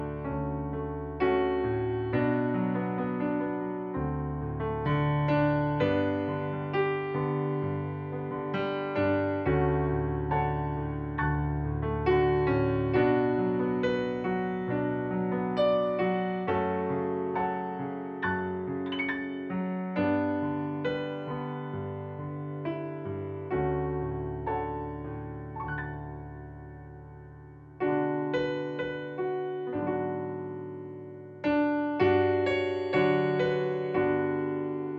Easy Listening